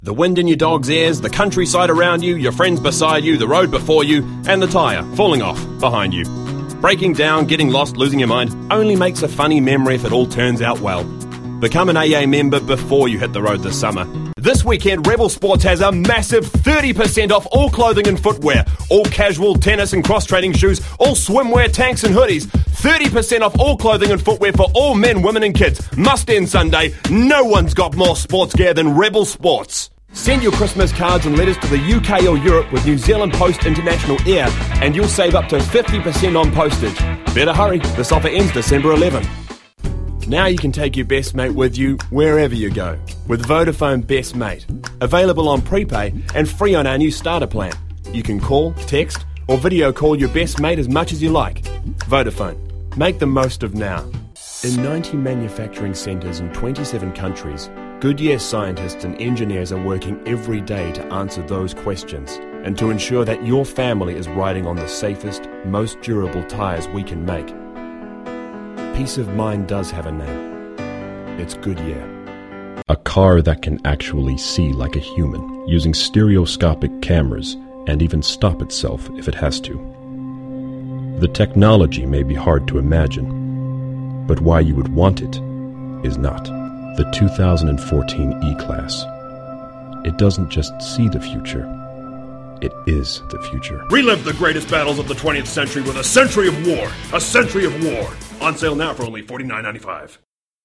Demo
Young Adult, Adult
australian | natural
british rp | natural
new zealand | natural
standard us | character
standard us | natural
epic
sincere
smooth/sophisticated
warm/friendly